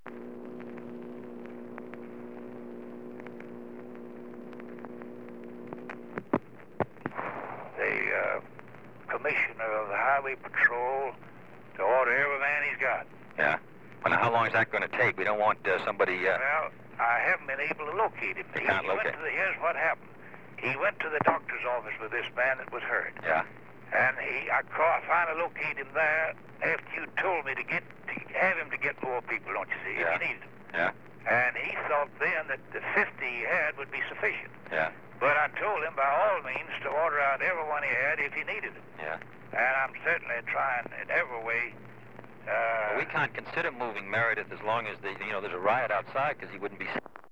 Conversation with Ross Barnett (12:14 am)
Secret White House Tapes | John F. Kennedy Presidency Conversation with Ross Barnett (12:14 am) Rewind 10 seconds Play/Pause Fast-forward 10 seconds 0:00 Download audio Previous Meetings: Tape 121/A57.